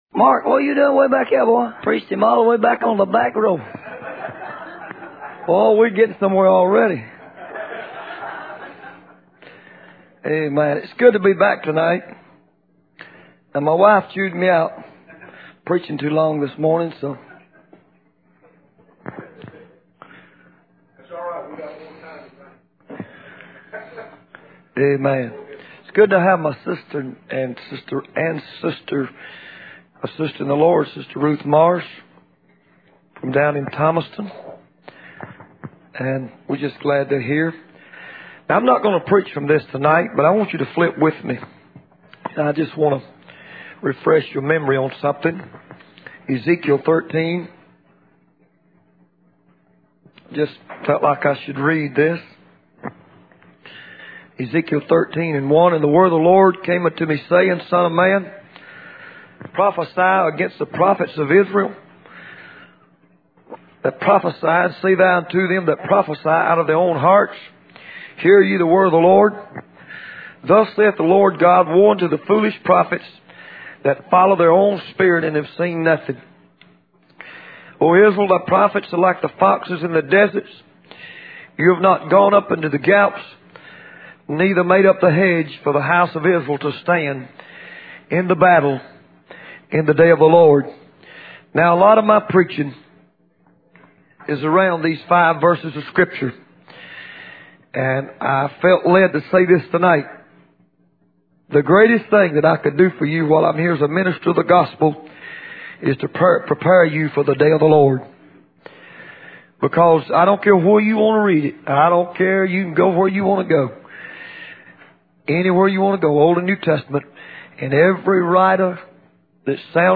In this sermon, the preacher describes encountering a man on Skid Road who was homeless and suffering from exposure to the elements.